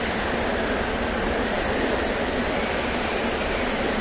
target_wind_fly_loop.ogg